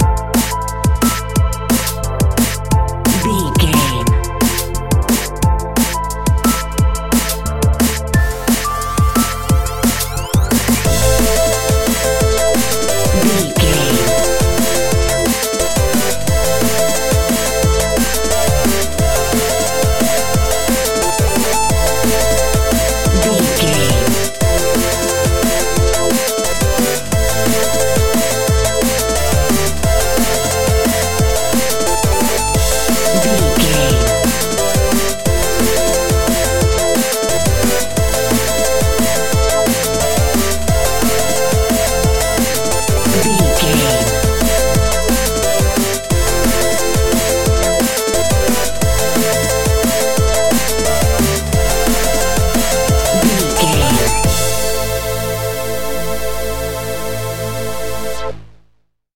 Fast paced
Aeolian/Minor
aggressive
dark
driving
energetic
futuristic
synthesiser
drum machine
break beat
electronic
sub bass
instrumentals